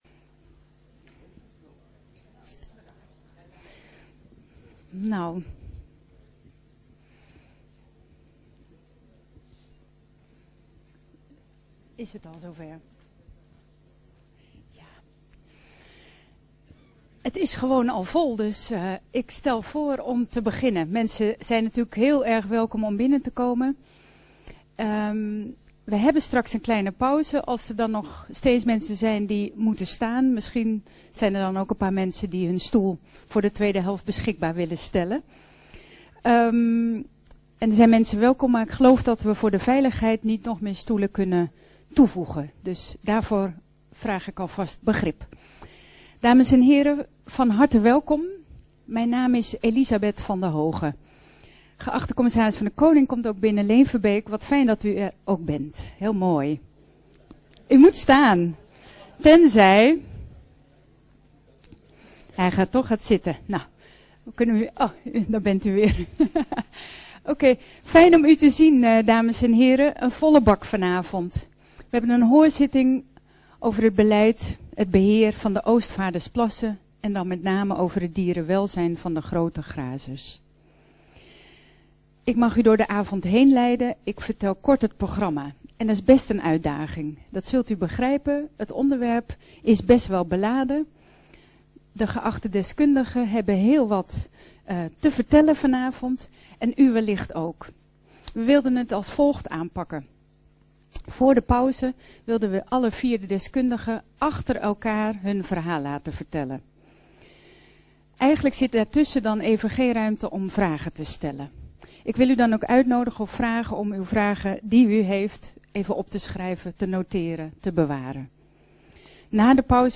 Hoorzitting Oostvaardersplassen (door: PvdA, GL en PvdD)
Locatie: Statenzaal